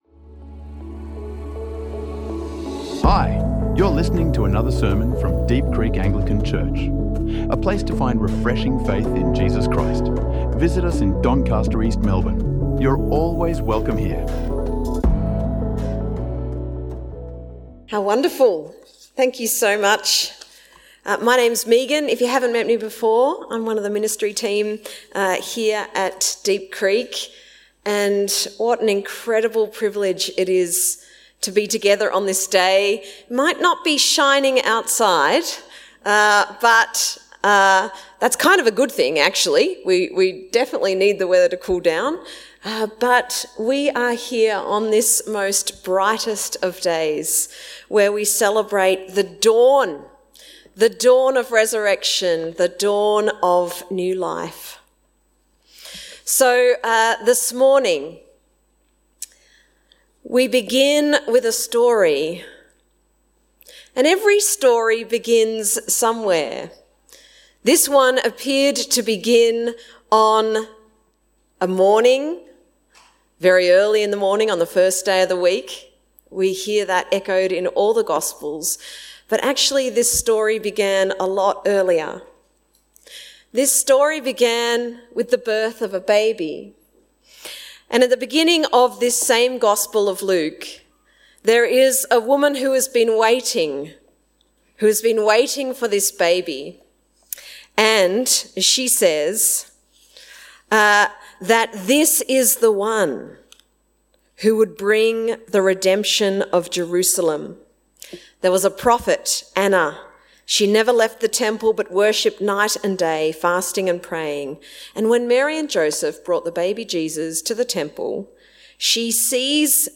This sermon unpacks the story of redemption, revealing how Jesus opens our minds to the scriptures, our wills to seek His presence, and our hearts to recognize Him in the breaking of bread —a journey for us every single day.